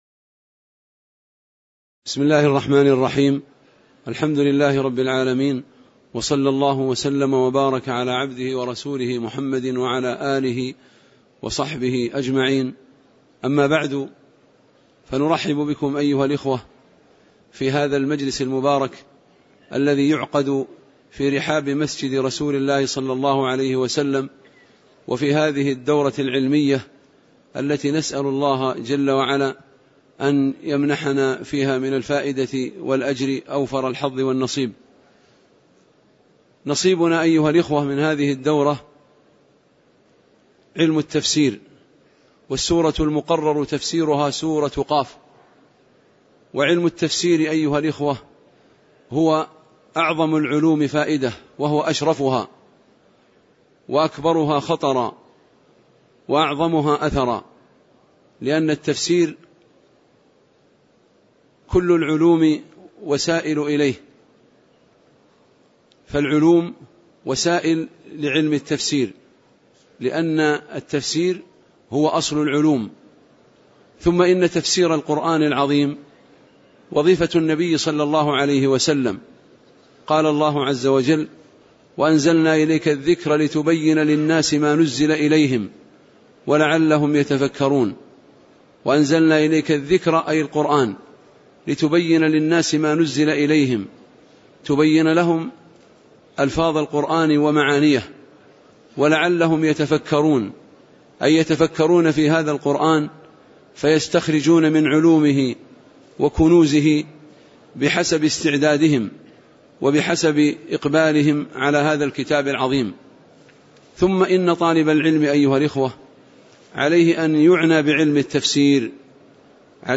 تاريخ النشر ٢٦ ربيع الثاني ١٤٣٩ هـ المكان: المسجد النبوي الشيخ